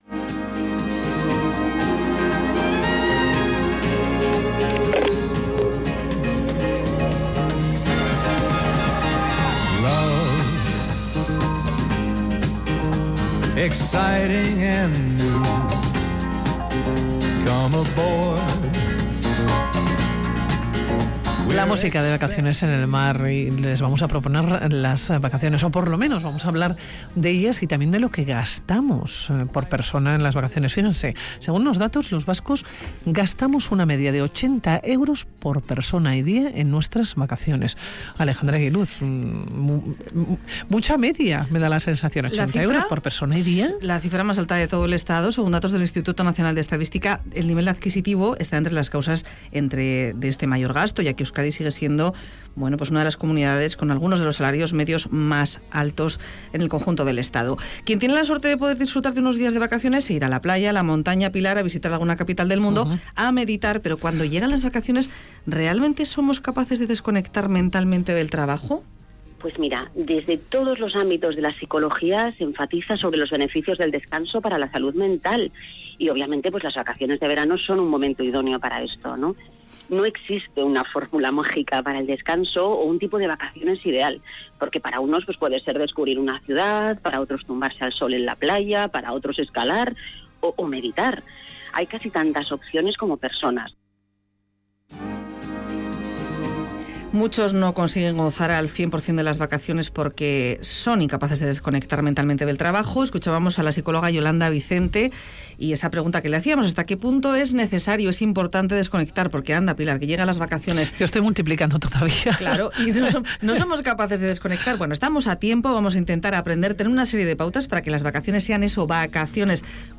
Radio Vitoria EL_MIRADOR ¿Sabemos desconectar en vacaciones? Última actualización: 05/07/2017 13:35 (UTC+2) Realizamos un reportaje con voces expertas sobre el disfrute en vacaciones y la desconexión.